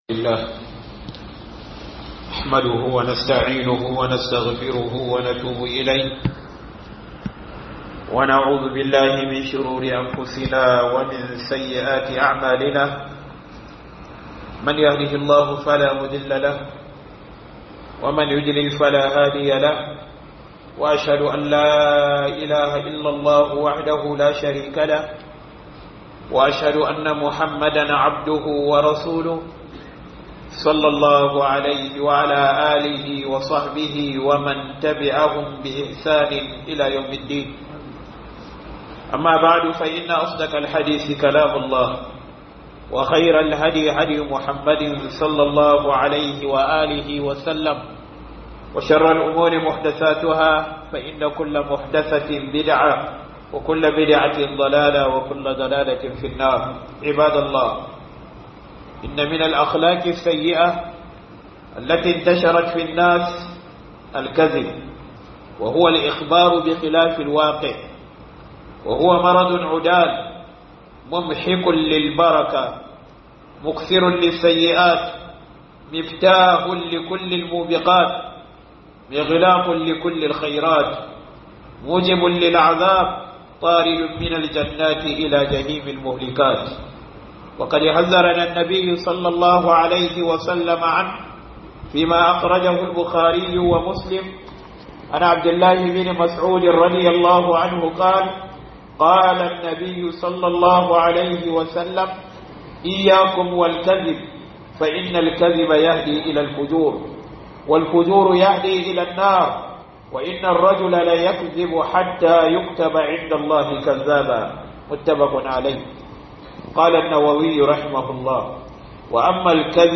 Karya da Illolinta - Huduba